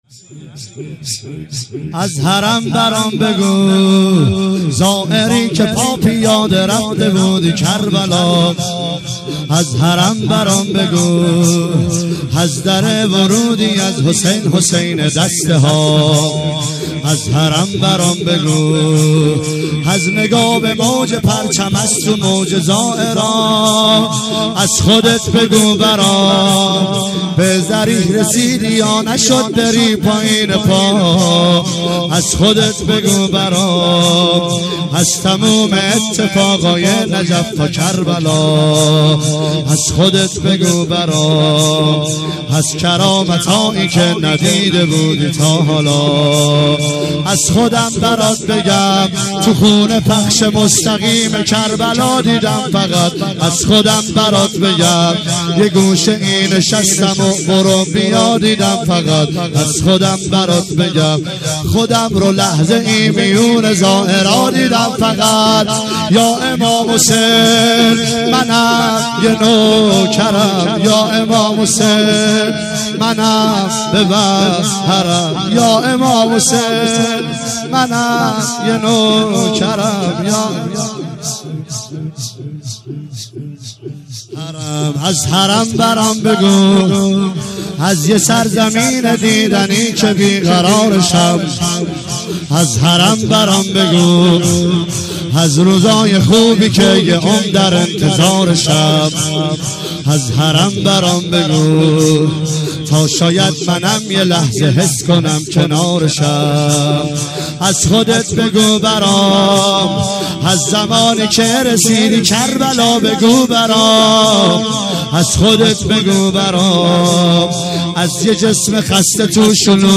دانلود مداحی جدید